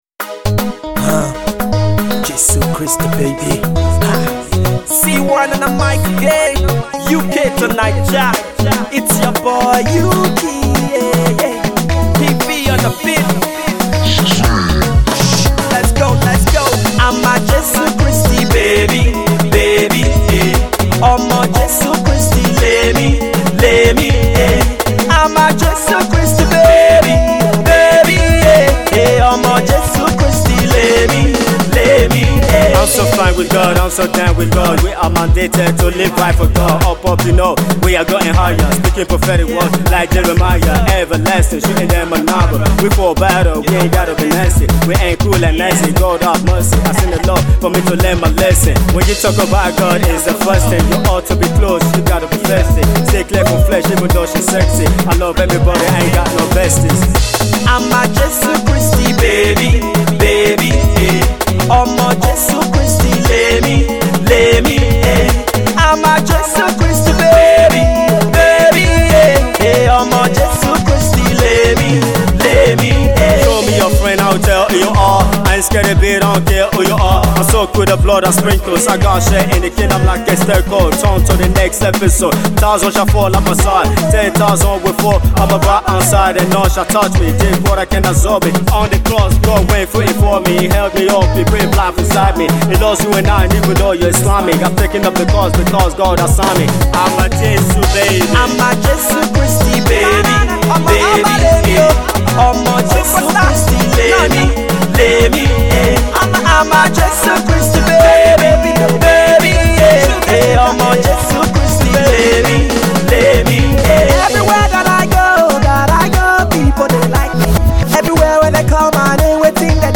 gospel
a dancable track that is sure to move you off your feet.
He started gospel hip hop some years back.